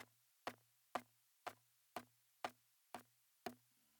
The guitarist hits the body of an acoustic guitar with the picking or plucking hand. The sound produced is very similar to a drum.
Body Slap With The Palm
BodySlapPalm.mp3